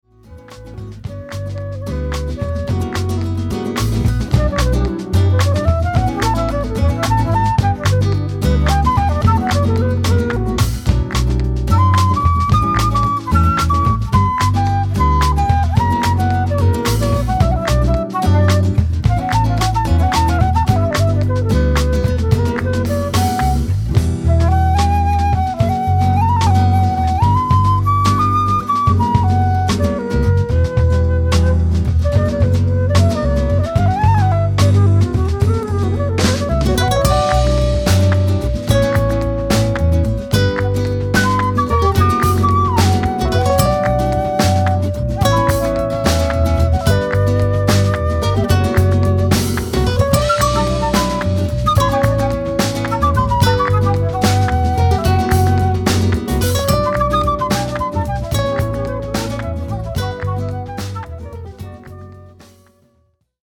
flute solo